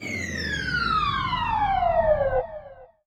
Waka TRAP TRANSITIONZ (6).wav